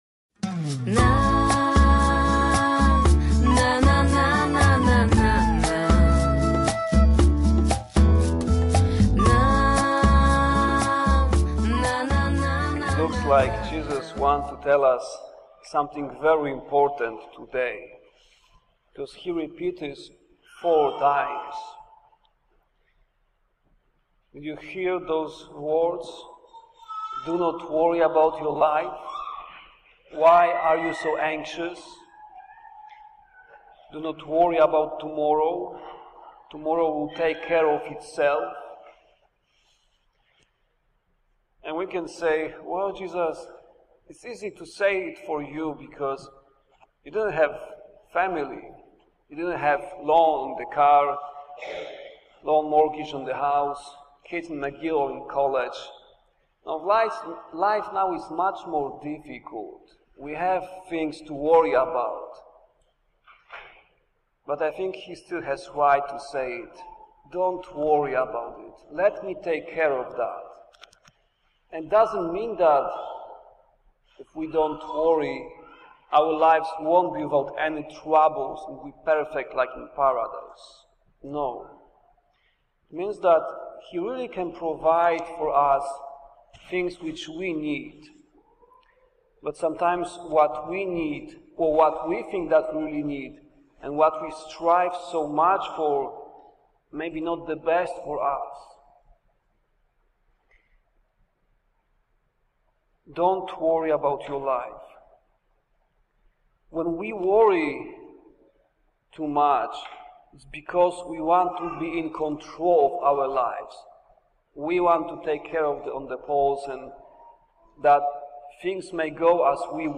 do-not-worry-about-your-life-homily-for-the-8-sunday.mp3